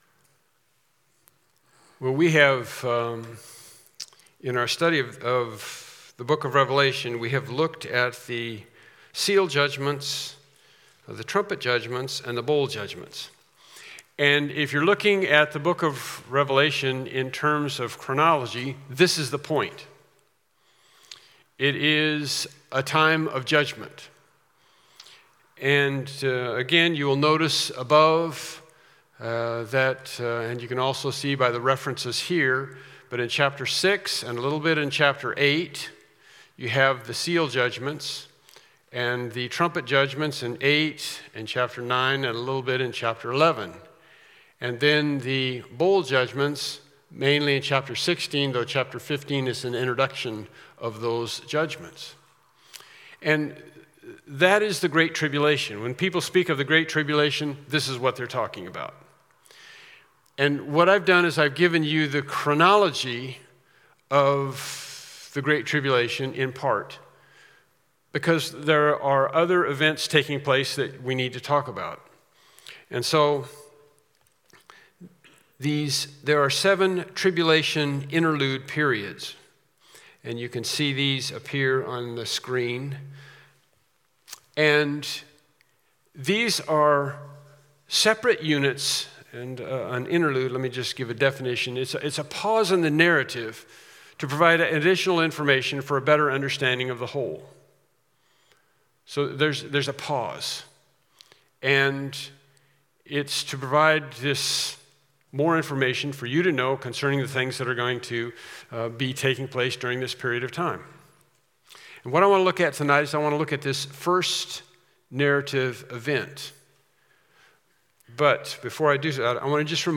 Service Type: Evening Worship Service